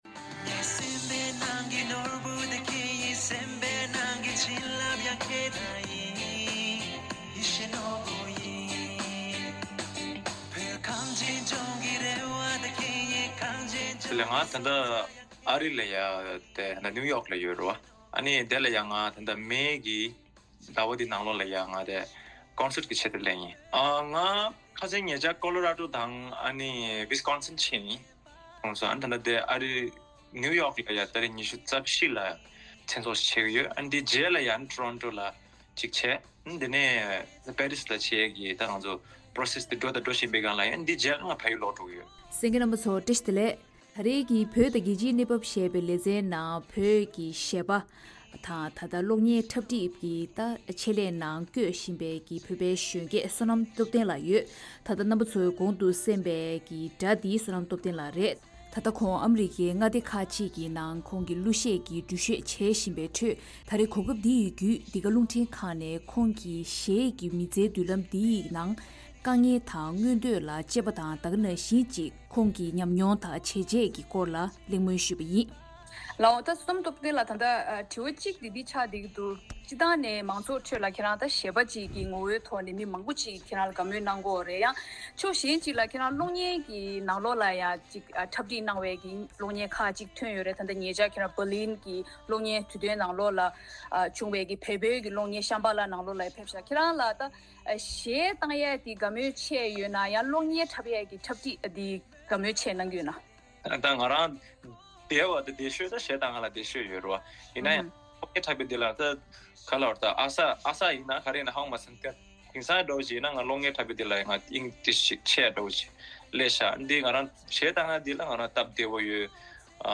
ལྷན་གླེང་གློང་གནང་བ་ཞིག་གསན་རོགས་གནང་།